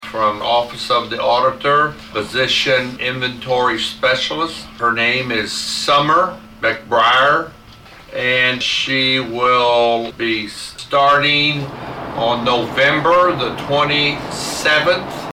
Presiding Commissioner Kile Guthrey Jr. announced the request.